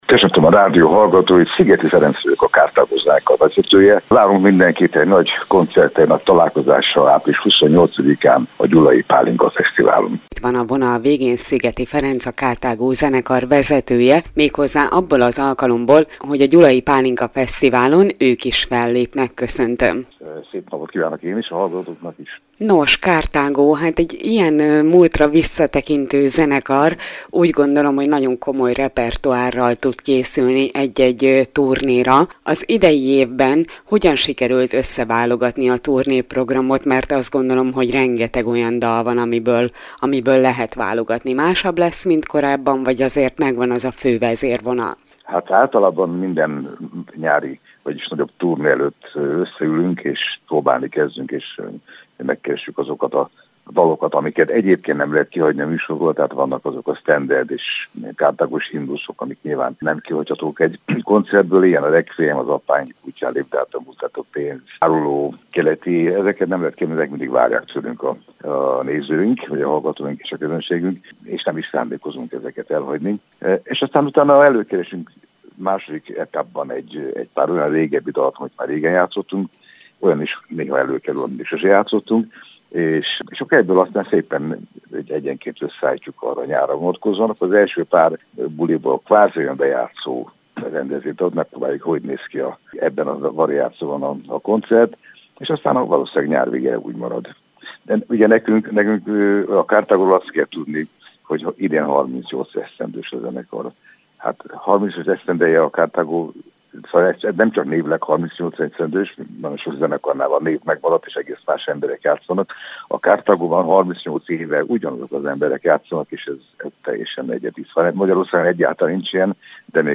Karthago a Gyulai Pálinkafesztiválon. Interjú Szigeti Ferenccel a zenekar vezetőjével - Körös Hírcentrum